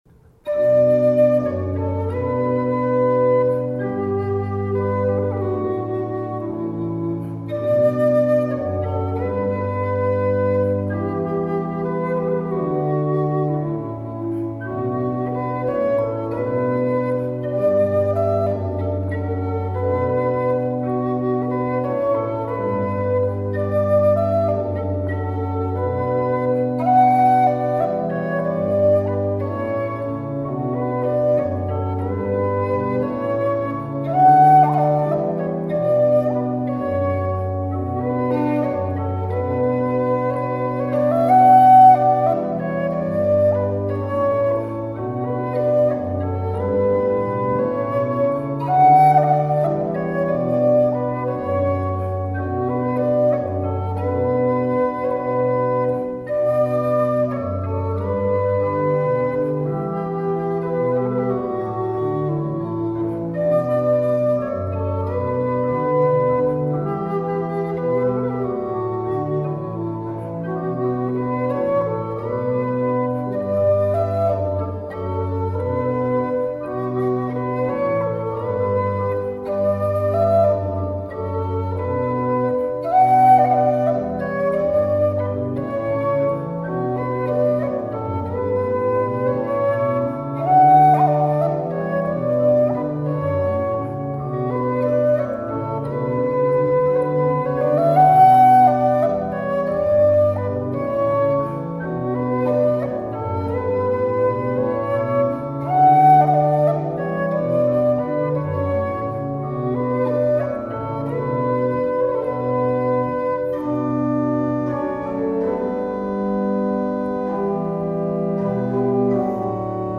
Aktuelle Probenaufnahmen: